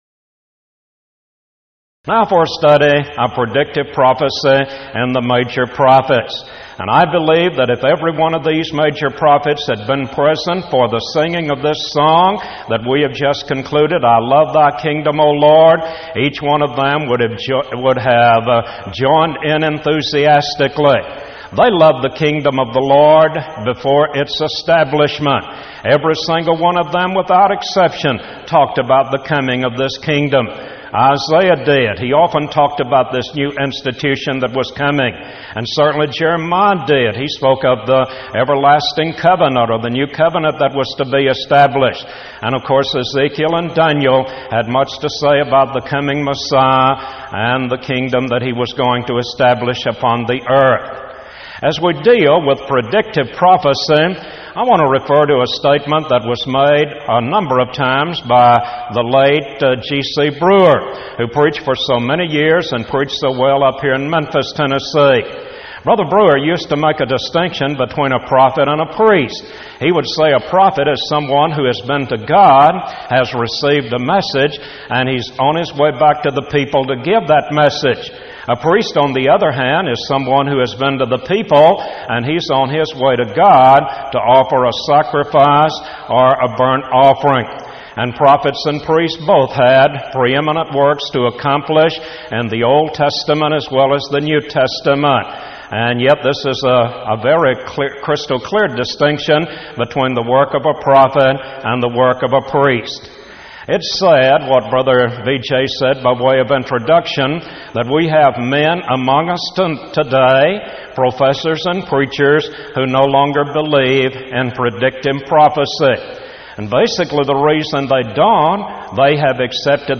Event: 1995 Power Lectures
lecture